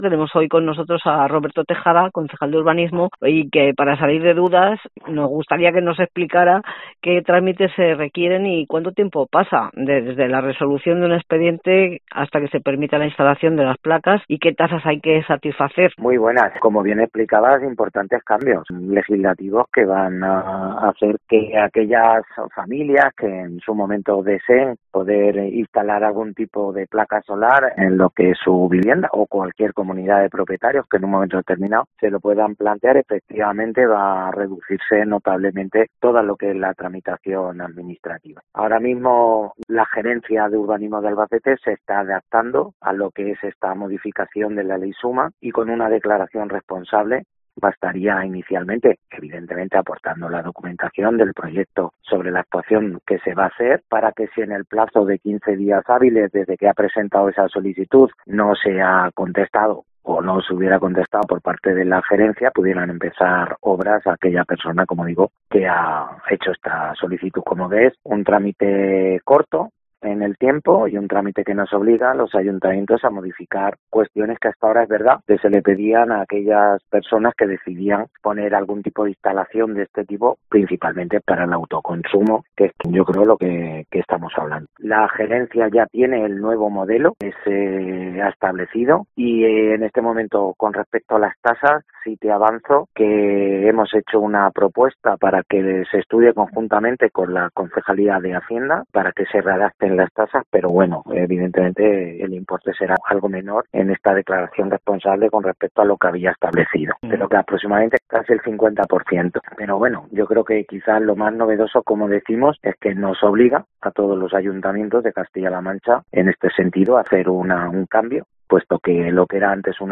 Roberto Tejada, concejal de urbanismo del ayuntamiento de Albacete nos cuenta en la entrevista que en COPE hemos mantenido, que el trámite ahora con la nueva ley será de unos quince días, reduciéndose sustancialmente el tiempo de espera para la instalación de sistemas de autogeneración, y por eso espera que en los próximos meses se incrementen las peticiones, y apunta que desde el Gobierno Regional se establecerán subvenciones para facilitar la autogeneración.